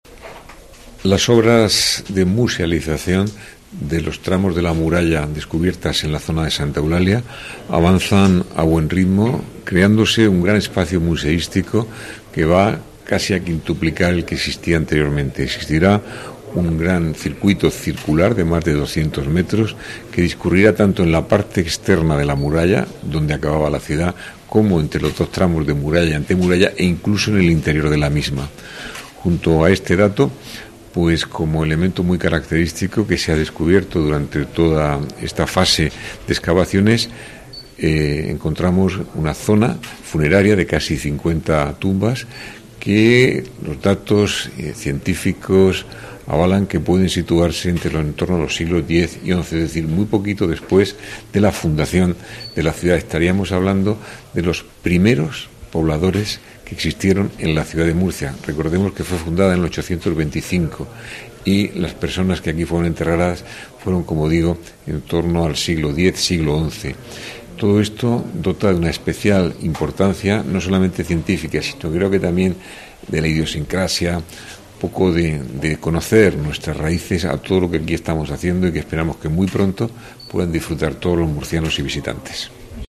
José Ballesta, alcalde de Murcia